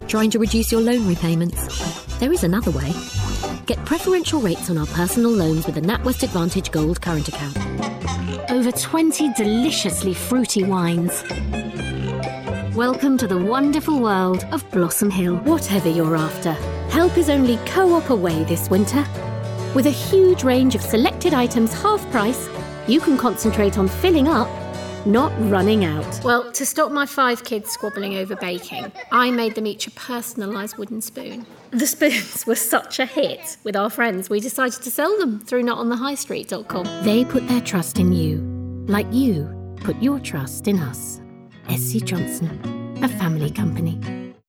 Commercial Showreel
Straight, Various
Showreel, Natural, Light Various